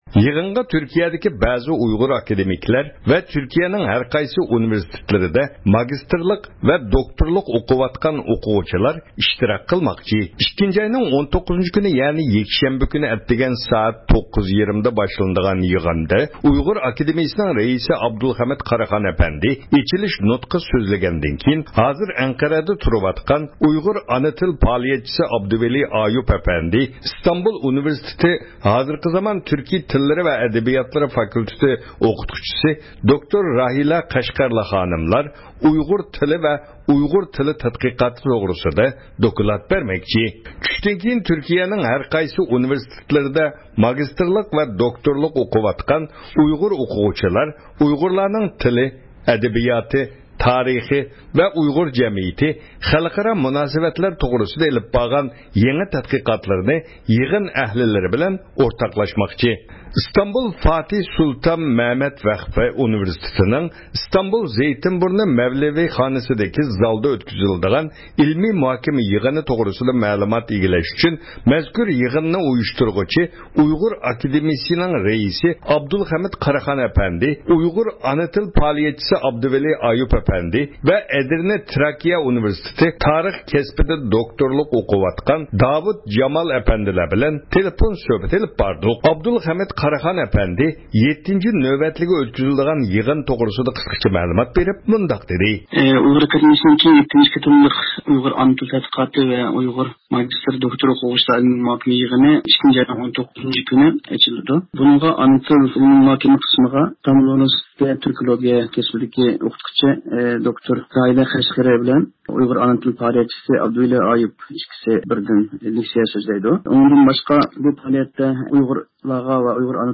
تېلېفون سۆھبىتى ئېلىپ بارغان.